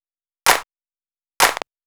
VTDS2 Song Kit 128 BPM Rap 1 Out Of 2
VTDS2 Song Kit 05 Rap 1 Out Of 2 Clap.wav